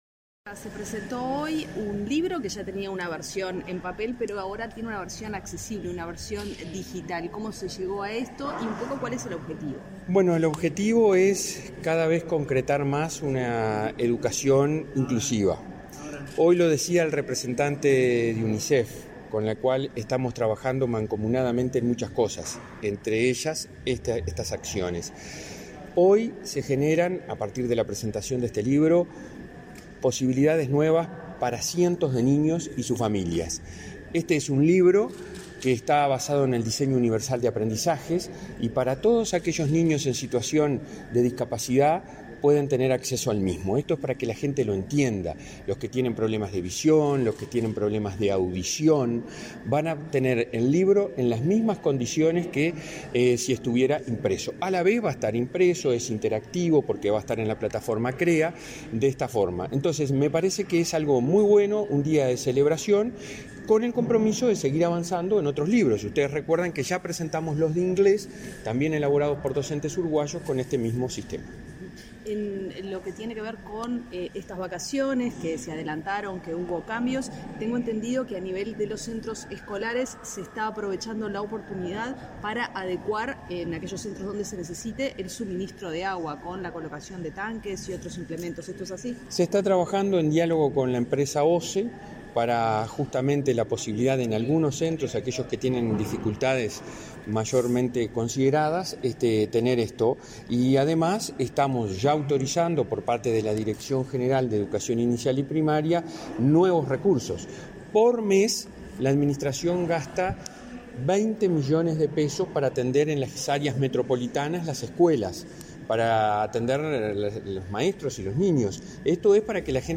Declaraciones a la prensa del presidente de ANEP, Robert Silva